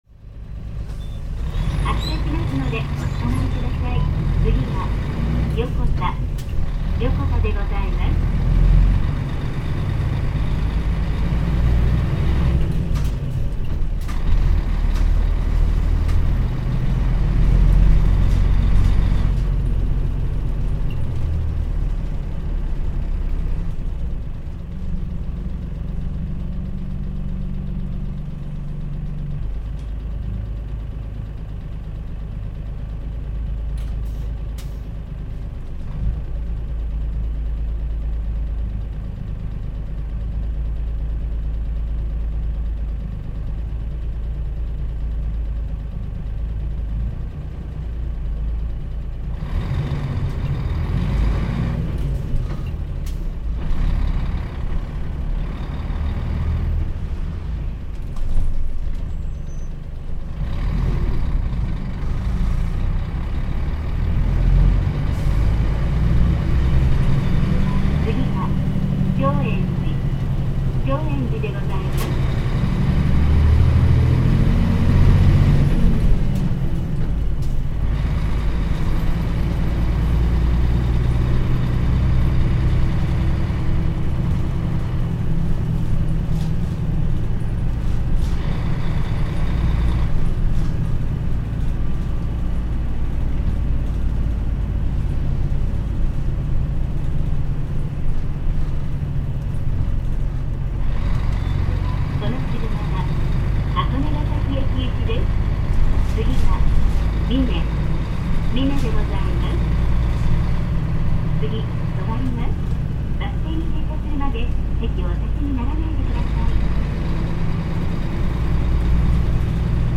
全国路線バス走行音立川バス